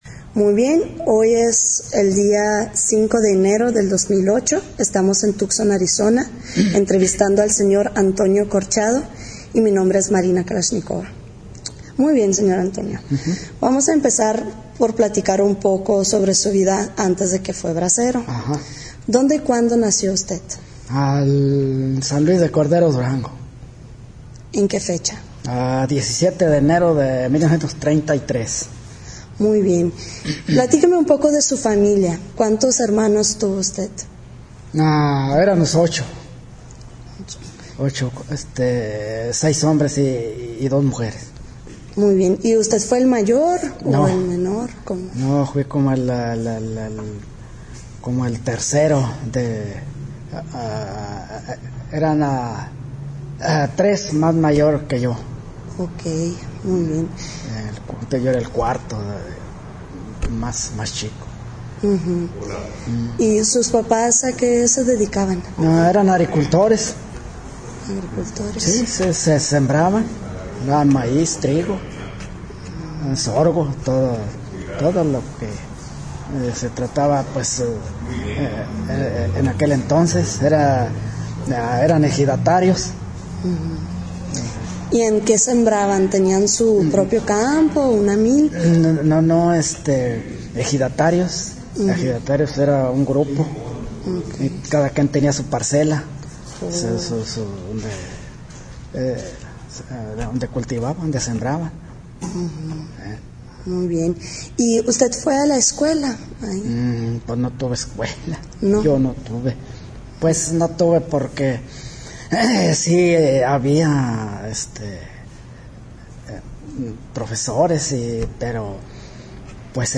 Location Tucson, Arizona